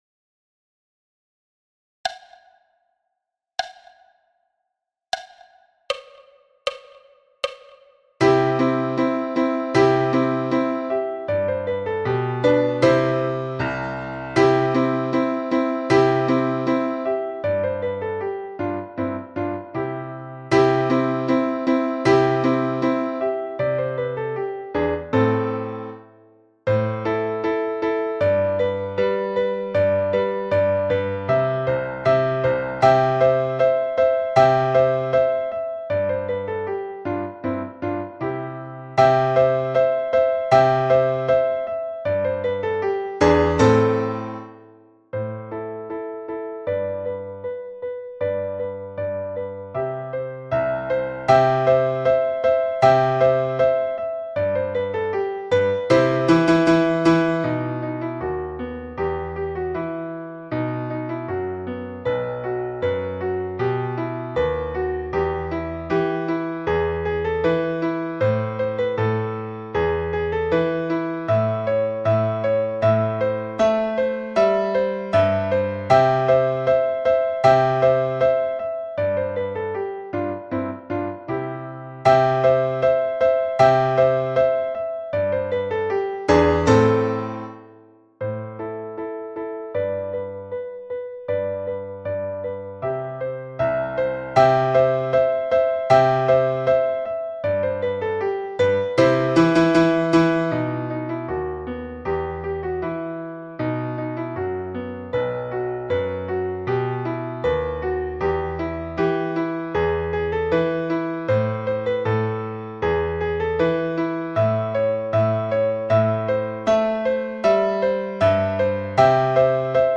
Turkish march – piano à 78 bpm
Turkish-march-piano-a-78-bpm.mp3